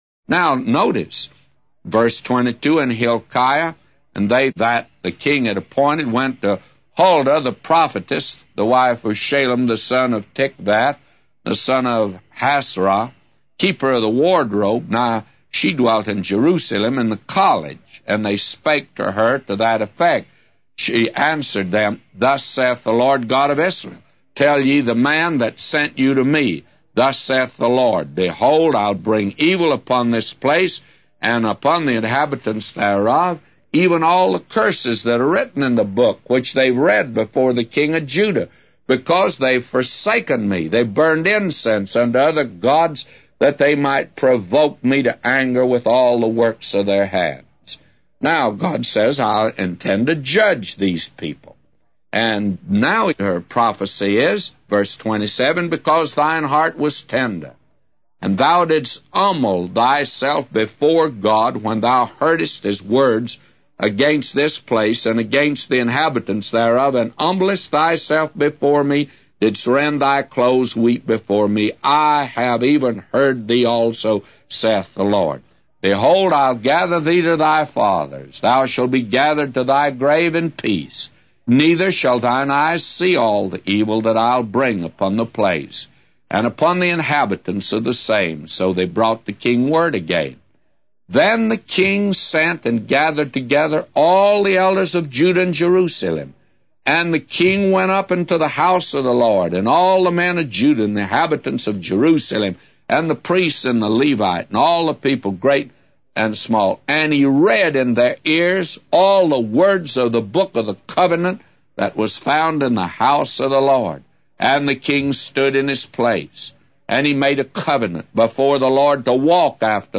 A Commentary By J Vernon MCgee For 2 Chronicles 34:22-999